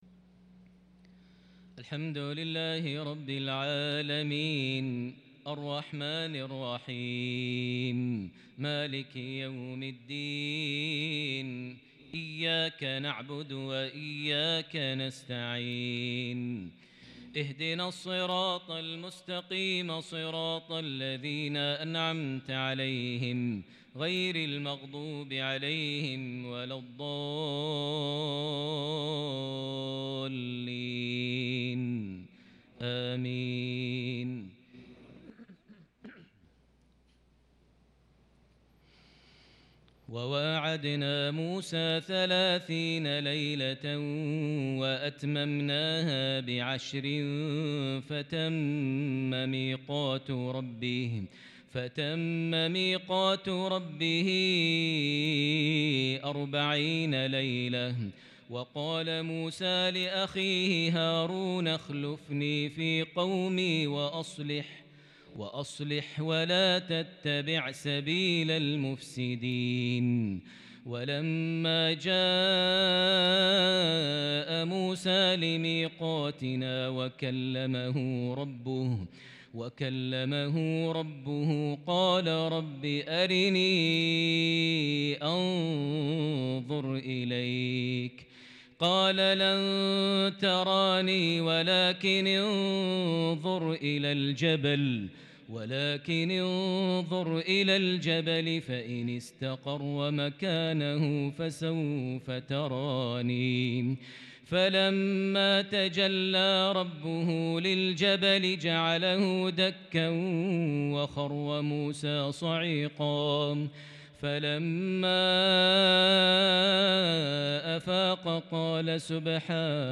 صلاة العشاء من سورة الأعراف|الثلاثاء 30 محرم 1443هـ | lsha 7-9-2021 prayer from Surah Al-Araf 142-153 > 1443 🕋 > الفروض - تلاوات الحرمين